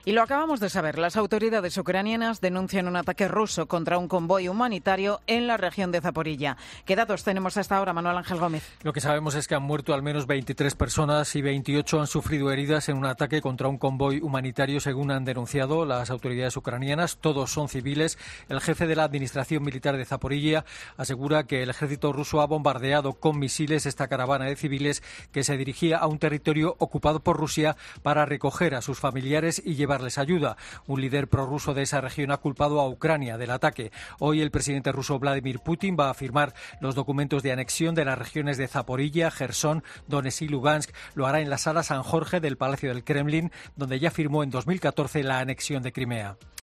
Internacional